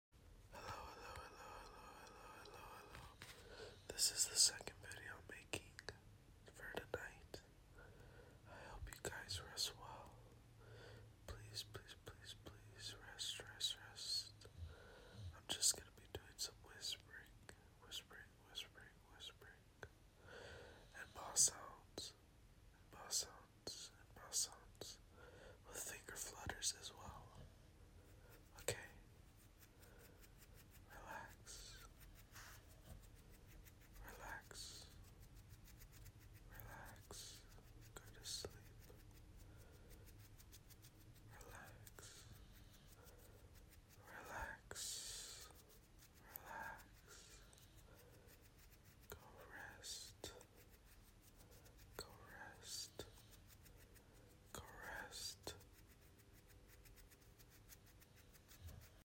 Sorry for my dogs snoring sound effects free download